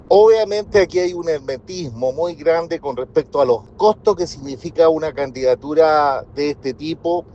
El diputado Christian Moreira (UDI), integrante de la Comisión de Relaciones Exteriores, cuestionó el hermetismo del Ejecutivo y pidió mayor claridad sobre los gastos asociados.